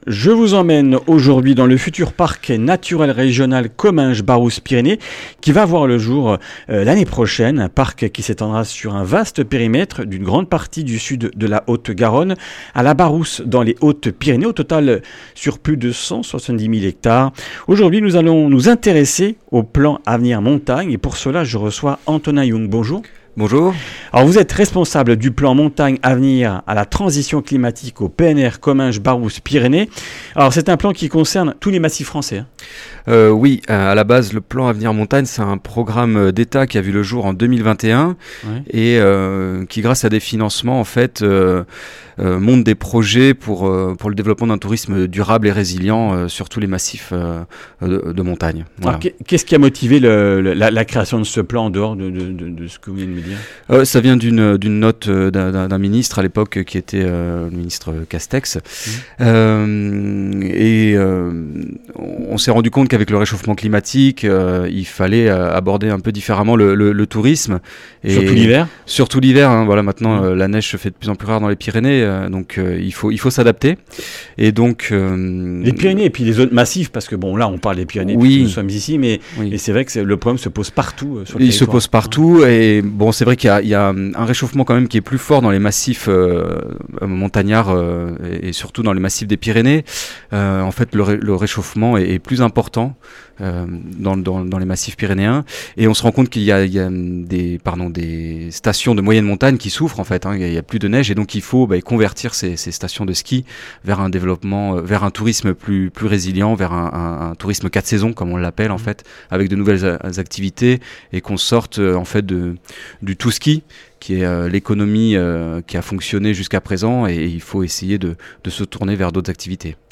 Une émission présentée par
Journaliste